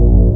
1 channel
Synth01C.wav